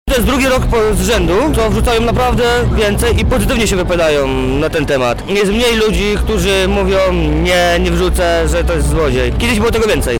Ludzie coraz częściej wspierają Wielką Orkiestrę Świątecznej Pomocy, o czym mówił nam jeden z wolontariuszy na Placu Defilad: